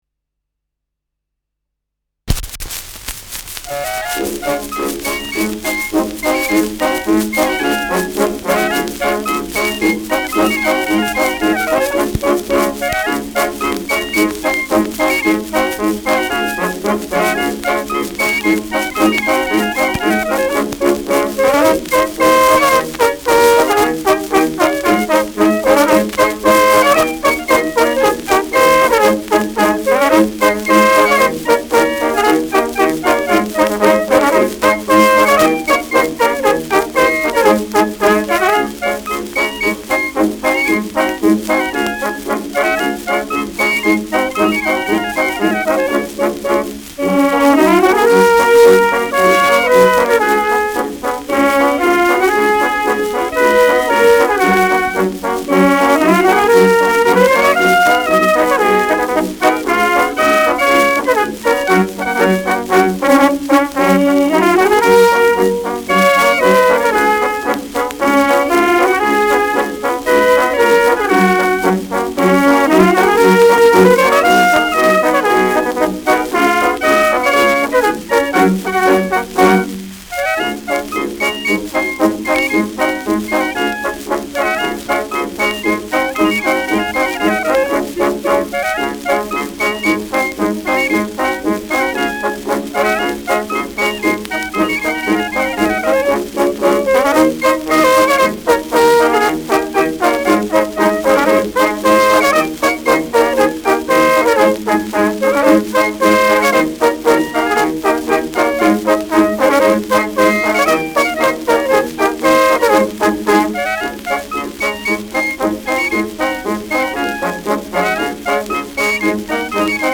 Schellackplatte
Tonrille: leichter Abrieb
leichtes Rauschen : leichtes Knistern
Kapelle Jais (Interpretation)
[München] (Aufnahmeort)